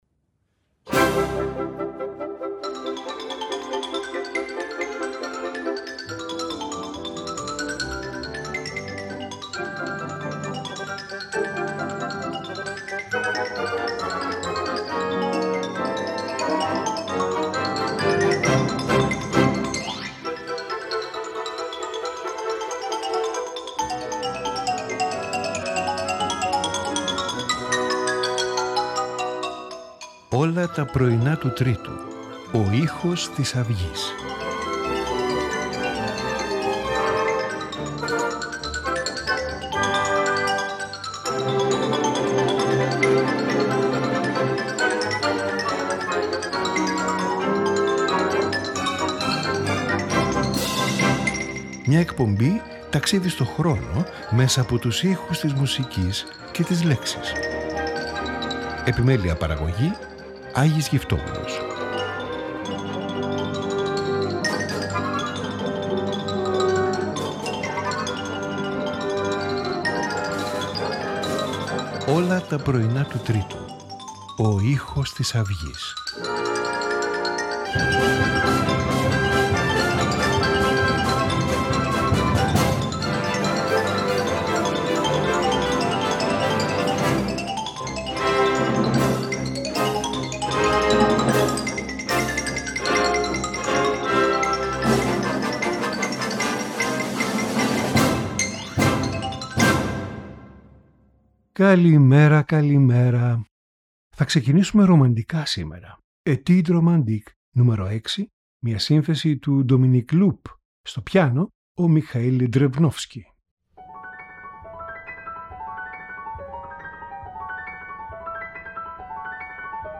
String Quartet
Violin Concerto
Sonata for Violin and Piano